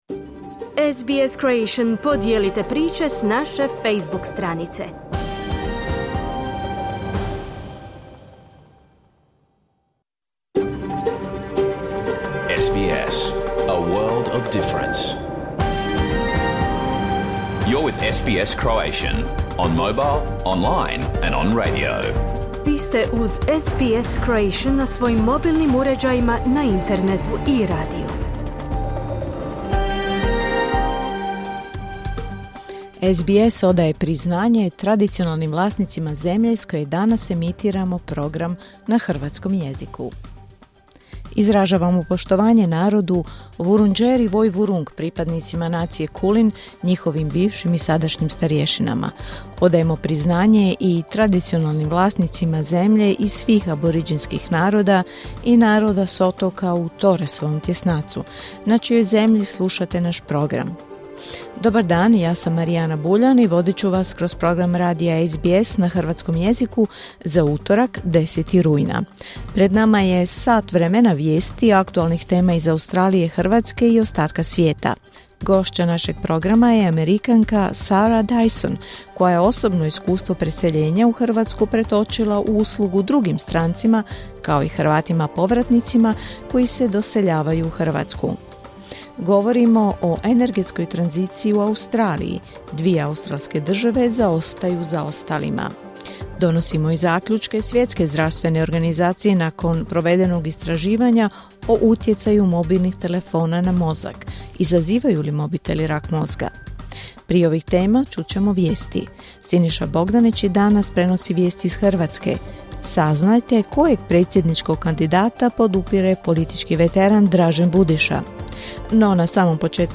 Pregled vijesti i aktualnih tema iz Australije, Hrvatske i ostatka svijeta. Emitirano uživo na radiju SBS1 u utorak, 10. rujna, u 11 sati po istočnoaustralskom vremenu.